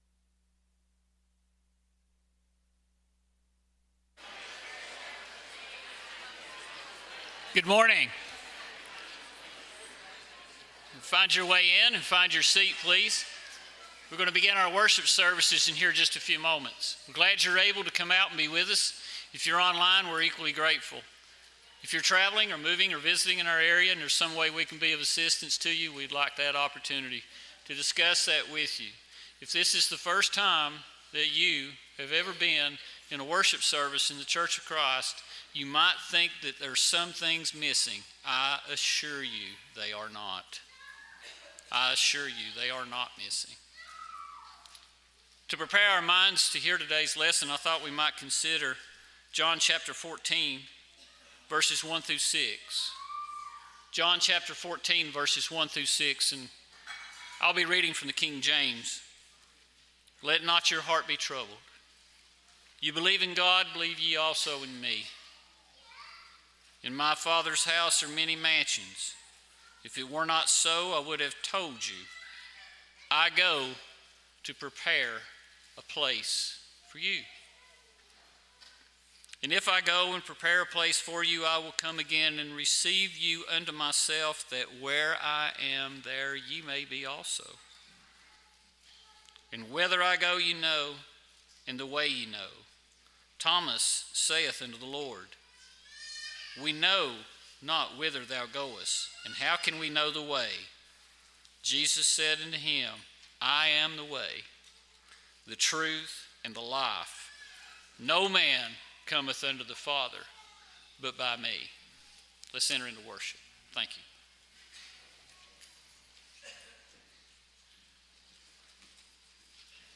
Romans 8:6, English Standard Version Series: Sunday AM Service